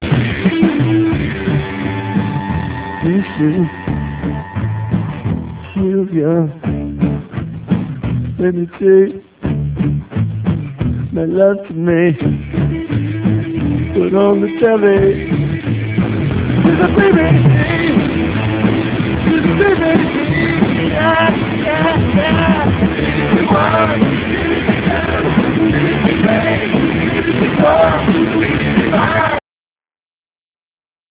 A song from the soundtrack